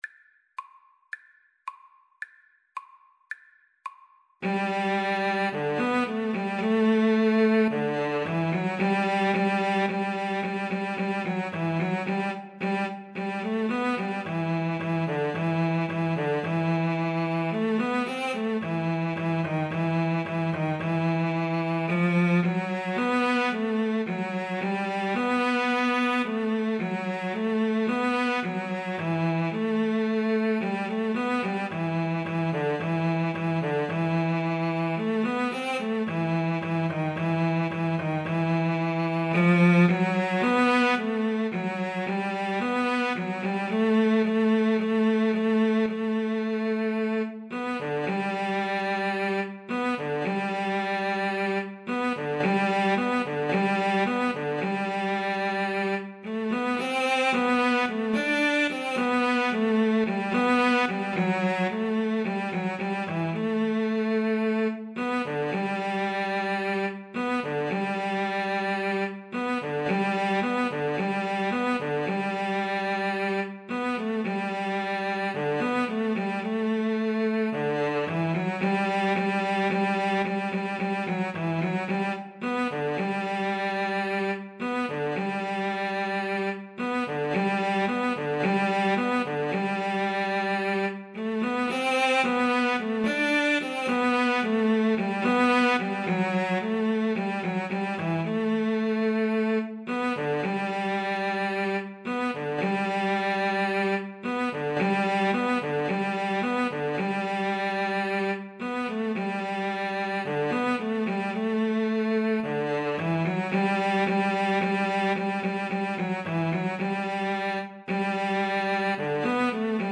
Moderato allegro =110